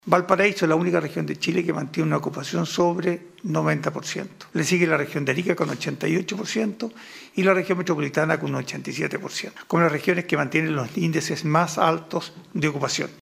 “Valparaíso es la única región de Chile que tiene sobre un 90% de ocupación”, dijo respecto de este tema el subsecretario de Redes Asistenciales, Alberto Dougnac.